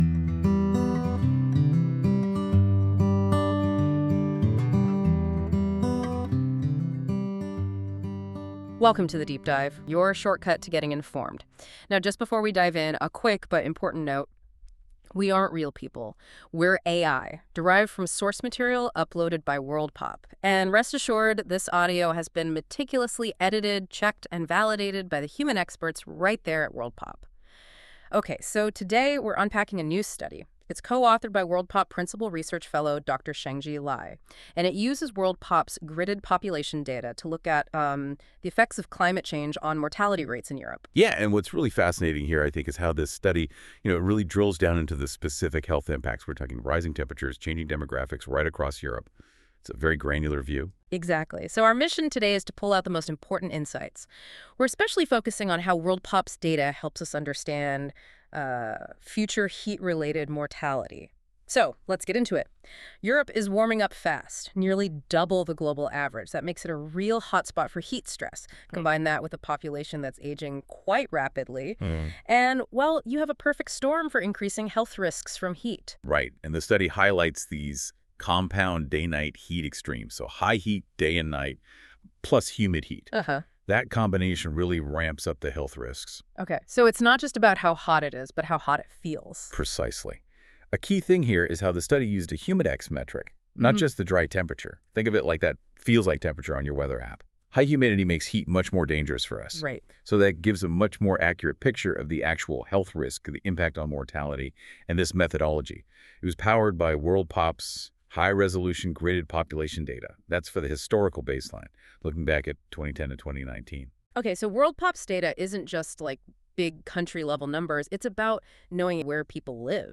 This feature uses AI to create a podcast-like audio conversation between two AI-derived hosts that summarise key points of a document - in this case the Disappearing People article in Science.
Music: My Guitar, Lowtone Music, Free Music Archive (CC BY-NC-ND)